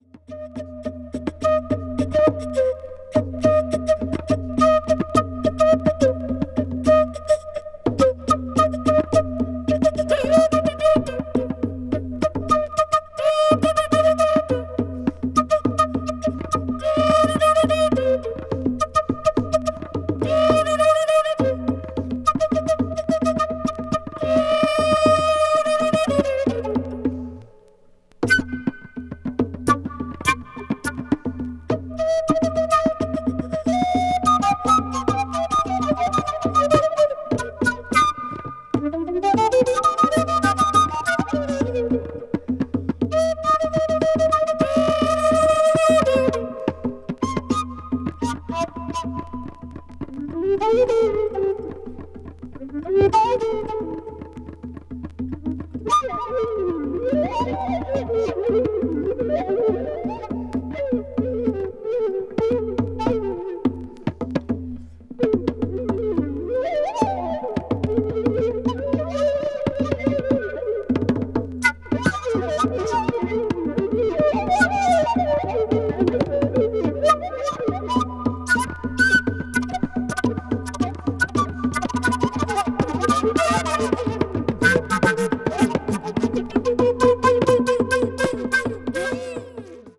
contemporary jazz   ethnic jazz   free jazz